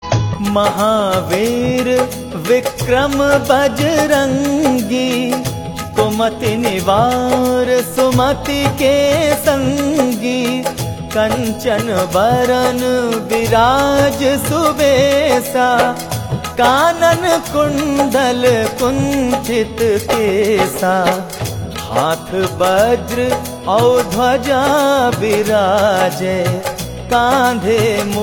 uplifting chants